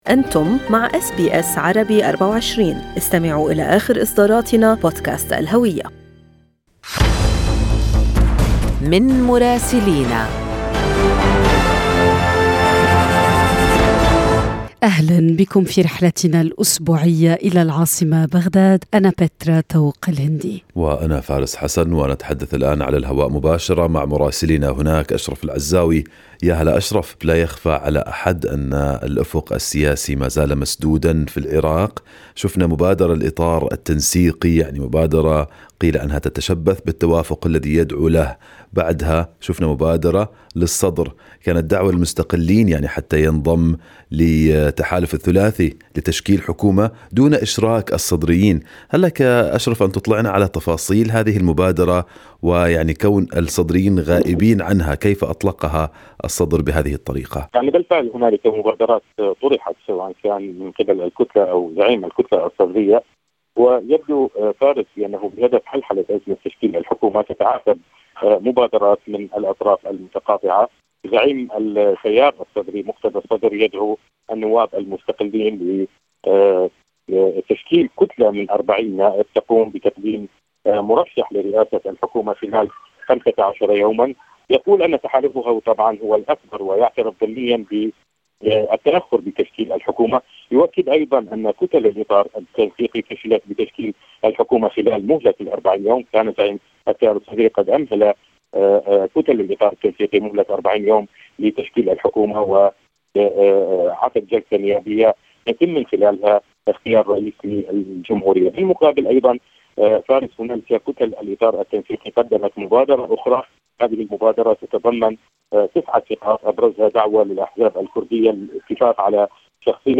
يمكنكم الاستماع إلى تقرير مراسلنا في بغداد بالضغط على التسجيل الصوتي أعلاه.